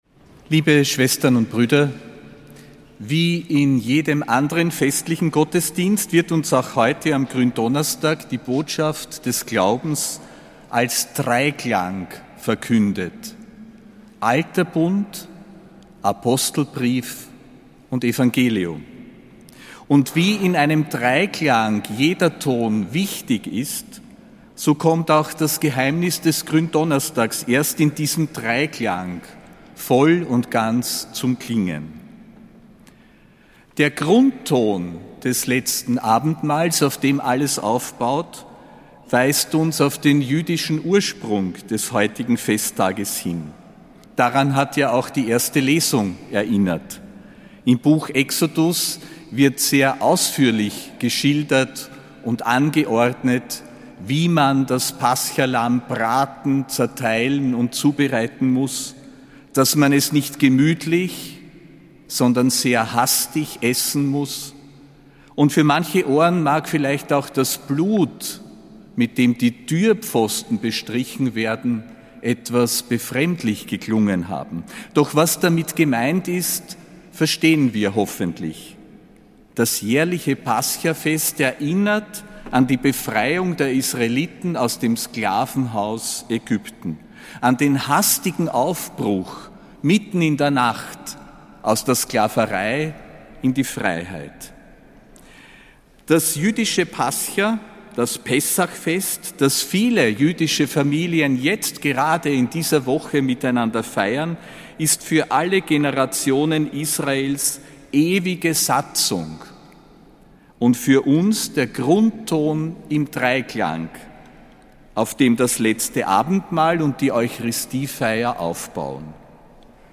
Predigt zum Gründonnerstag (17. April 2025)